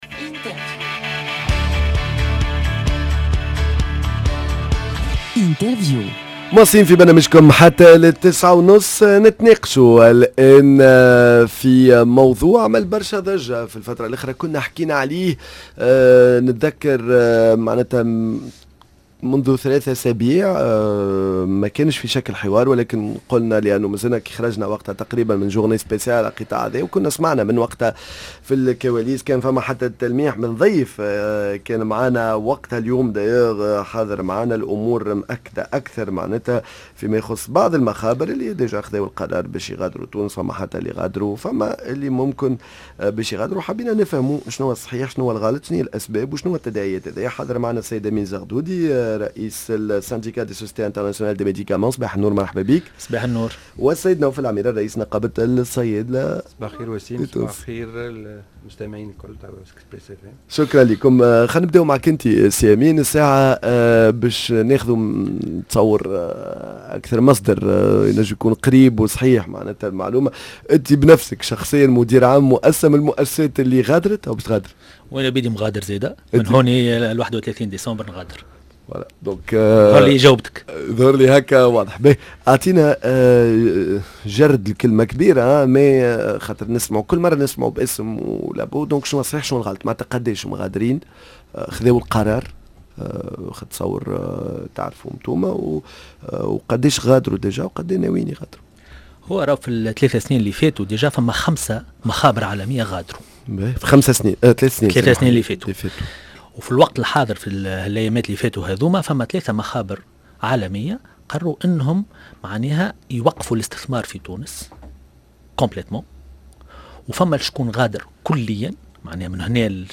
● Discussion autour du départ de laboratoires pharmaceutiques de la Tunisie ? Quelles raisons et quelles conséquences ?